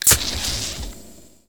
inject.ogg